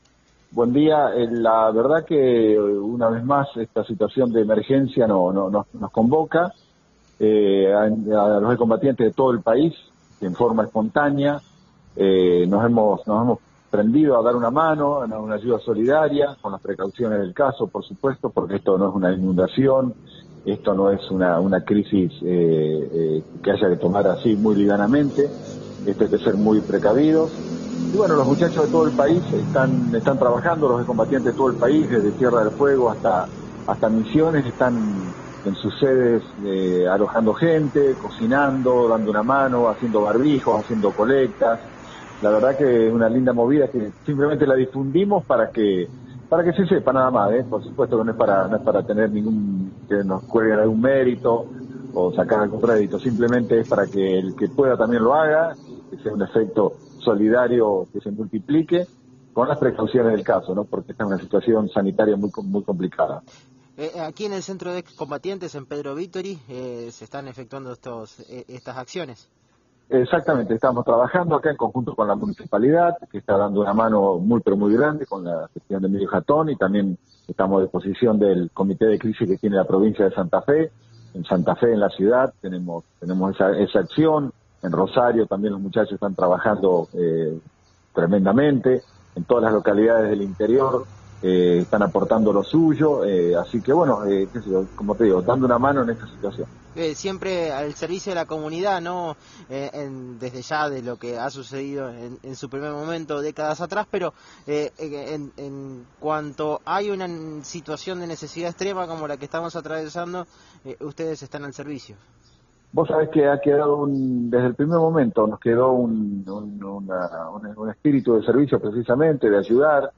Veteranos de Malvinas de todo el país se organizaron para dar una mano a los que más lo necesitan en esta cuarentena. Adolfo Schweighofer, que desde enero de este año es el presidente de la Comisión Nacional de Ex Combatientes de Malvinas del Ministerio del Interior, en dialogó con Radio Eme señaló que «La verdad es que una vez más esta situación de emergencia nos convoca a los ex combatientes de todo el país en forma espontánea”.